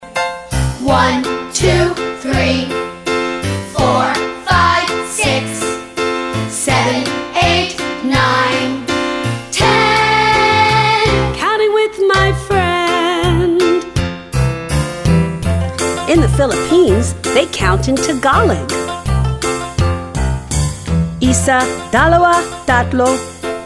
Here are two versions of this counting song.